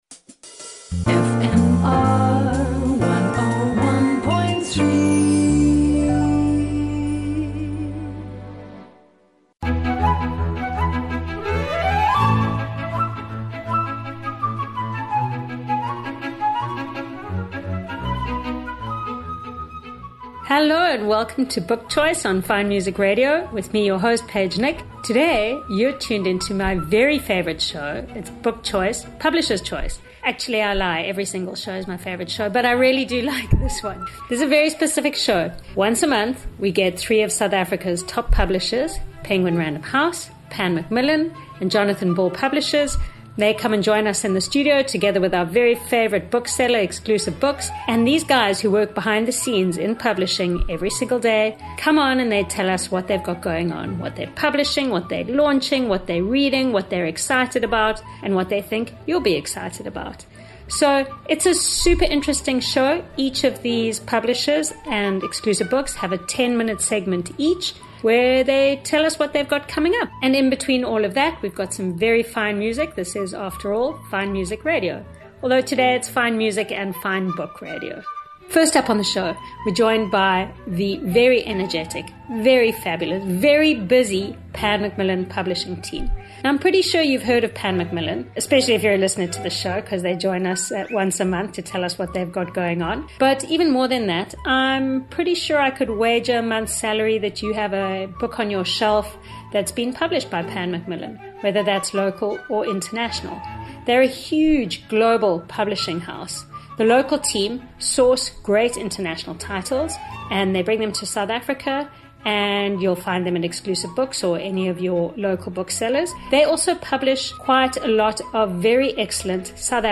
Cape Town’s top book reviewers will entertain and inform you as they cheerfully chat about the newest and nicest fiction and non-fiction on current book shelves. You love author interviews?